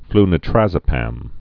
(flnĭ-trăzə-păm)